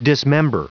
Prononciation du mot dismember en anglais (fichier audio)
Prononciation du mot : dismember